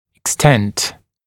[ɪk’stent] [ek-][ик’стэнт] [эк-]размер, степень, мера